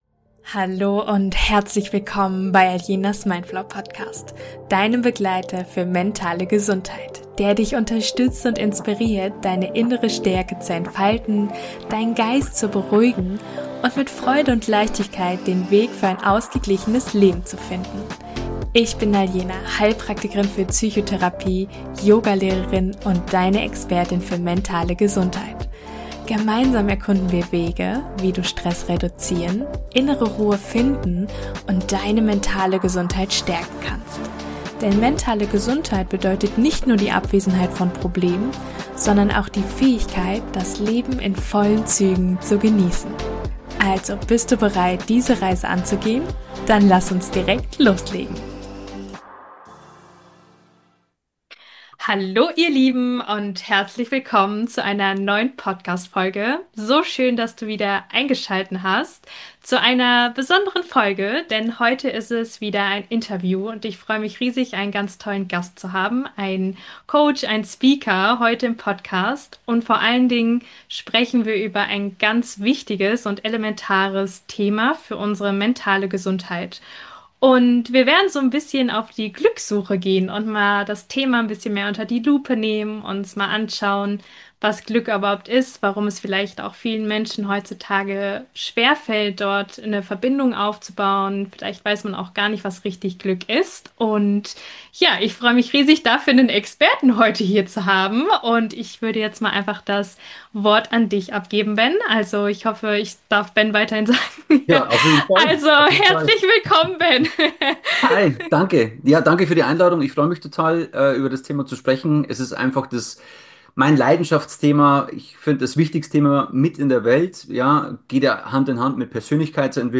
Glück auf 1000 (Um)Wegen - Interview